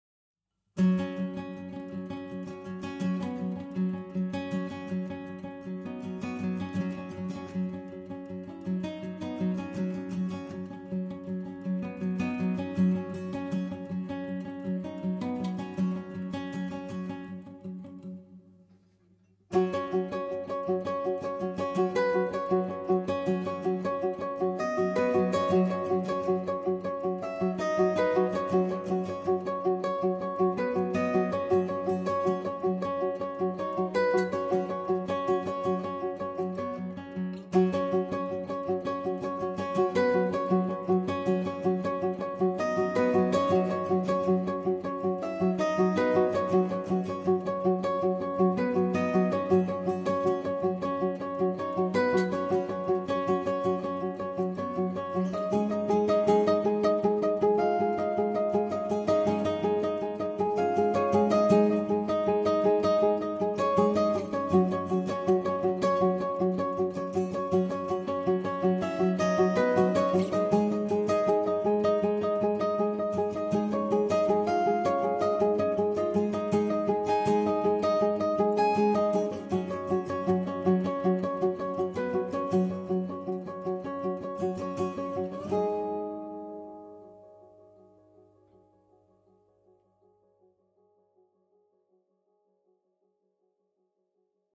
guitars solo